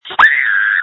Screams from December 24, 2020
• When you call, we record you making sounds. Hopefully screaming.